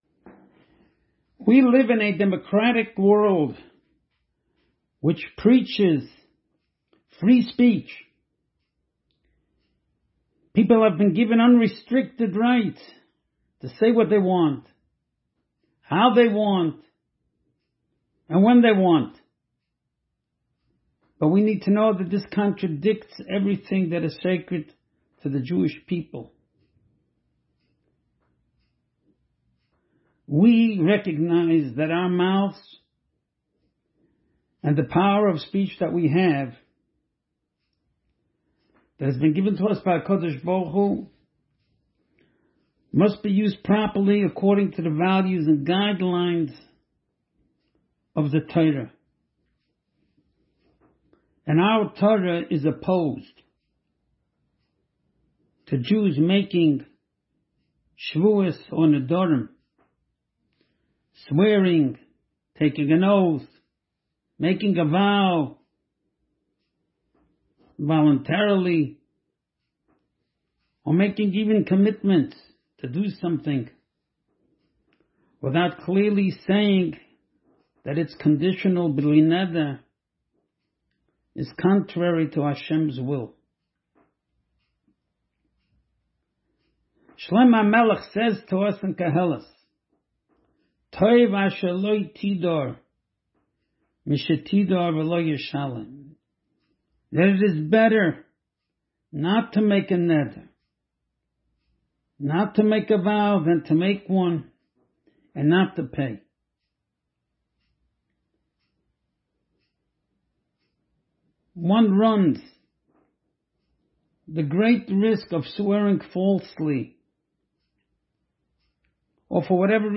POWERFUL SPEECH מטות